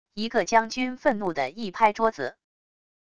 一个将军愤怒的一拍桌子wav音频